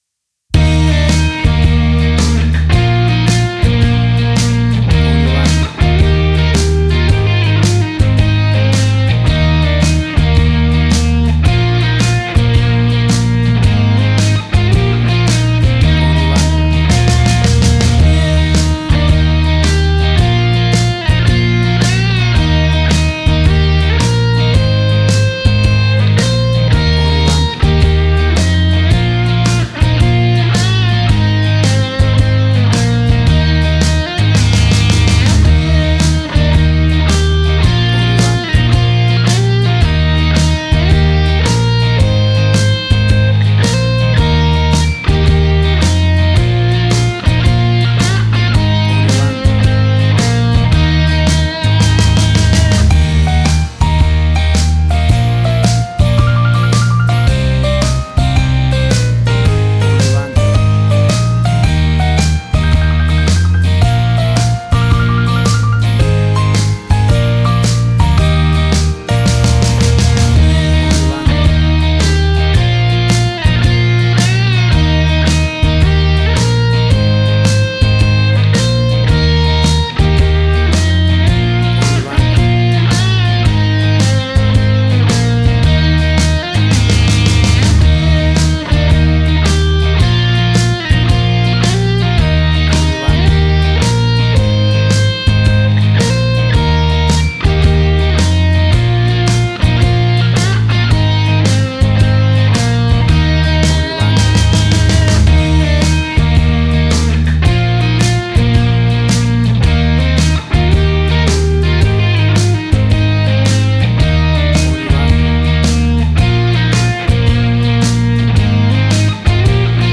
A big and powerful rocking version
WAV Sample Rate: 16-Bit stereo, 44.1 kHz
Tempo (BPM): 110